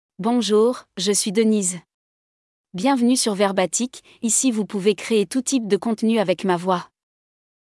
FemaleFrench (France)
DeniseFemale French AI voice
Denise is a female AI voice for French (France).
Voice sample
Denise delivers clear pronunciation with authentic France French intonation, making your content sound professionally produced.